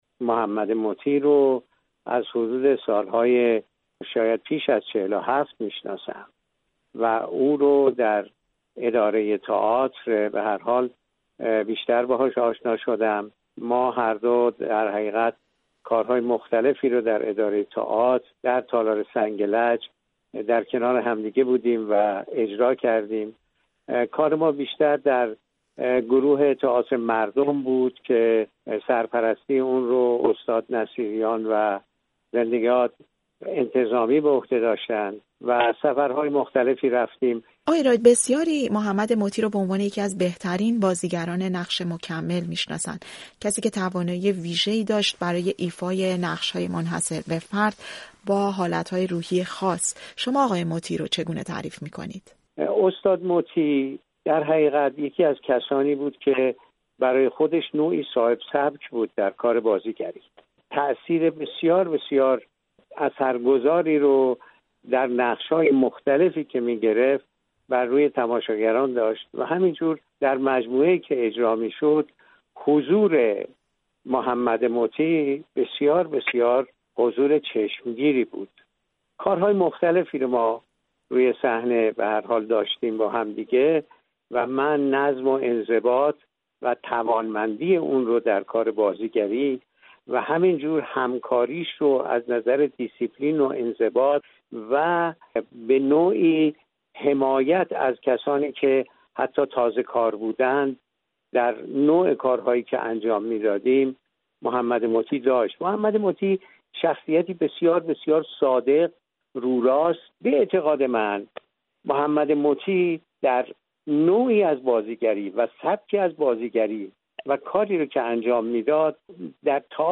گفت‌وگو با ایرج راد، بازیگر، کارگردان و از دوستان قدیمی محمد مطیع